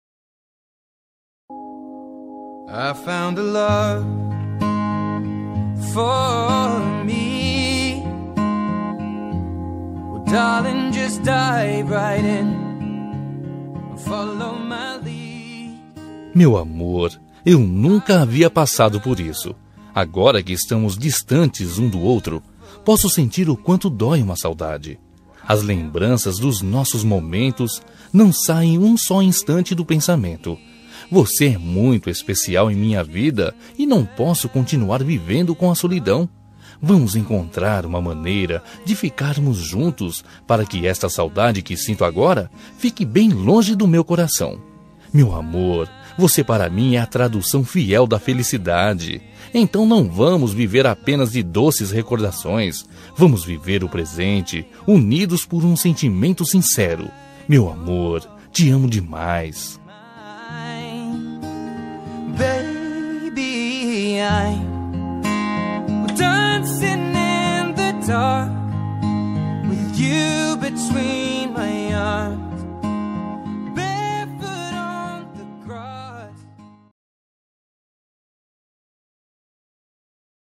Telemensagem Romântica Distante – Voz Masculina – Cód: 201898